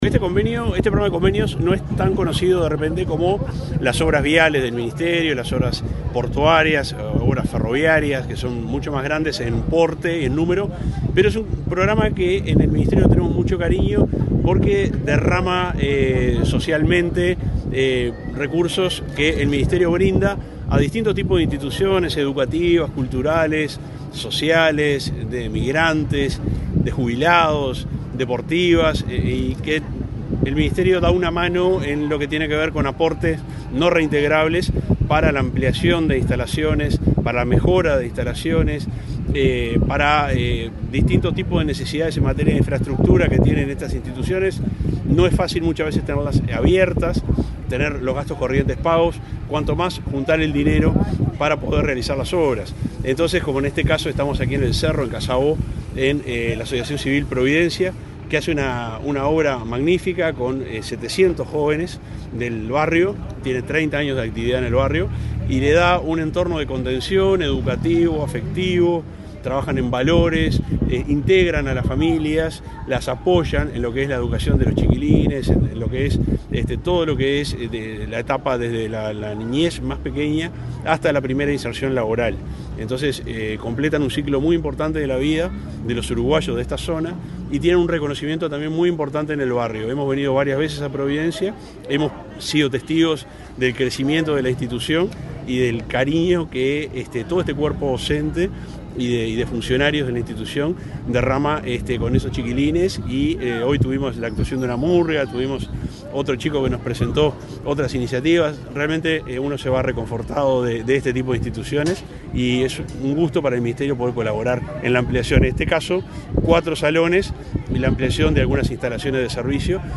Declaraciones del subsecretario de Transporte, José Luis Olaizola
El Ministerio de Transporte y Obras Públicas y el Centro Educativo Providencia inauguraron las obras de ampliación del Club de Niños, en el barrio Casabó de Montevideo, en el marco del programa de convenios de la cartera. El subsecretario Juan José Olaizola, dialogó con Comunicación Presidencial acerca del alcance de este plan.